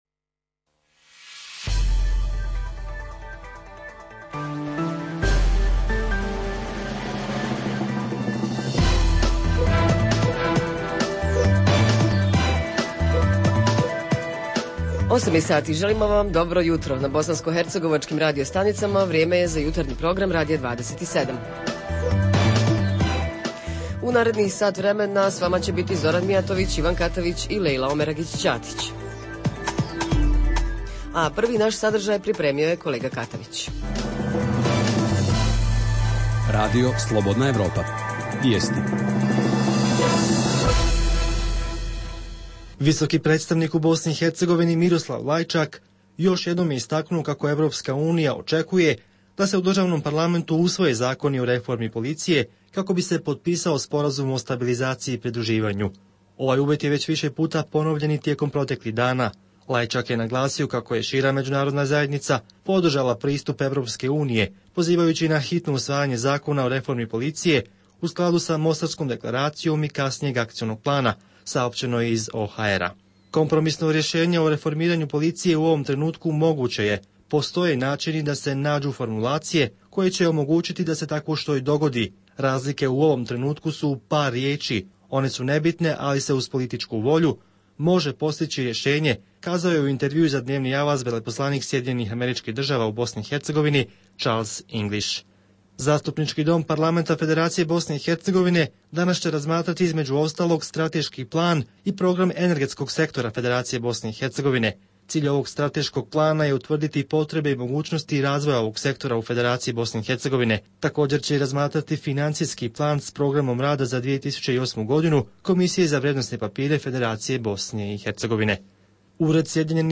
Redovni sadržaji jutarnjeg programa za BiH su i vijesti i muzika.